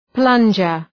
Προφορά
{‘plʌndʒər}